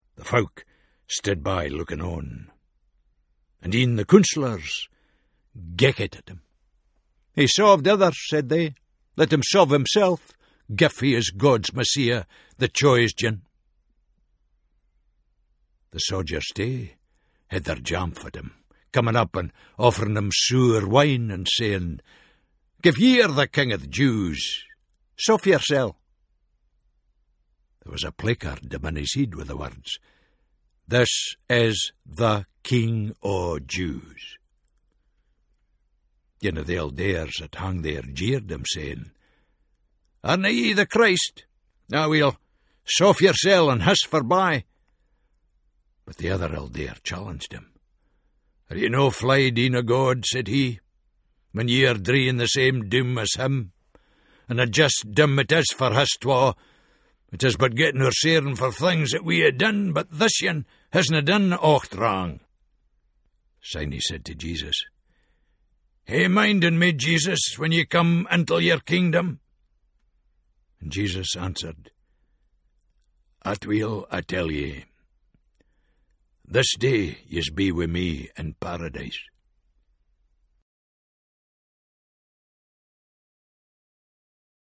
In the recordings of the Scots New Testament, Tom Fleming brings the well known stories of the gospels to life in a distinct Scots voice.